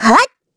Xerah-Vox_Attack4_kr.wav